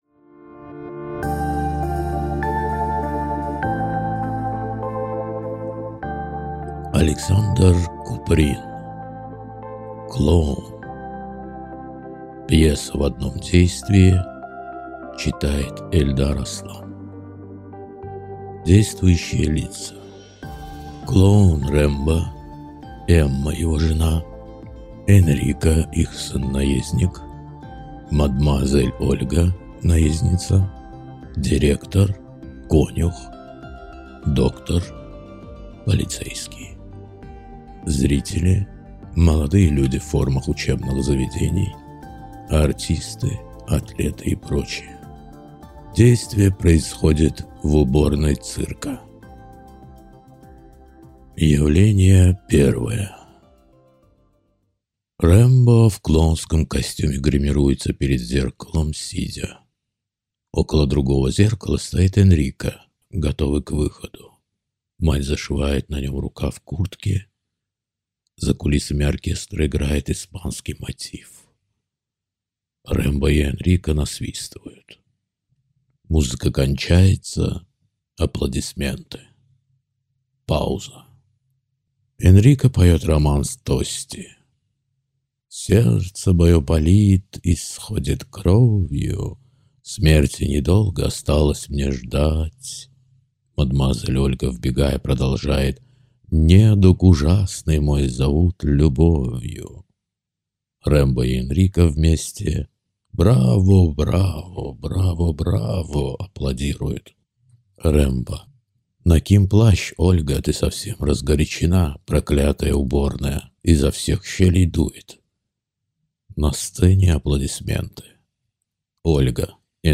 Аудиокнига Клоун | Библиотека аудиокниг